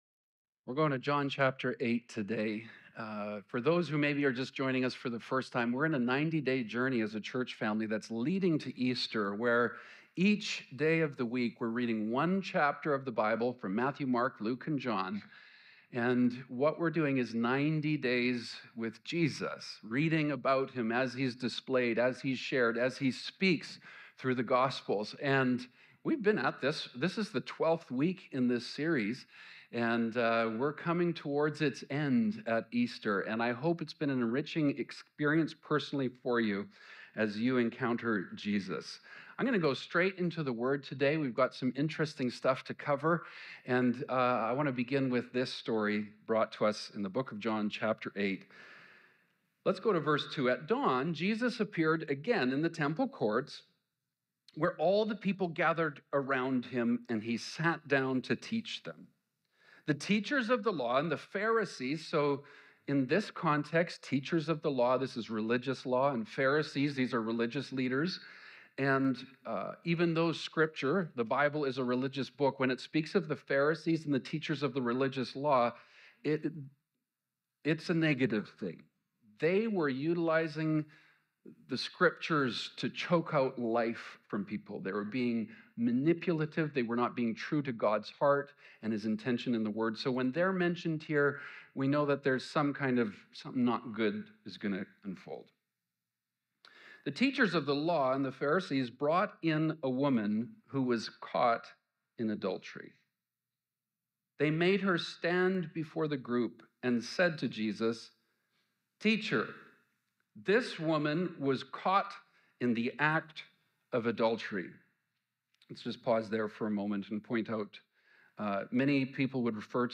Guest Speaker
Current Sermon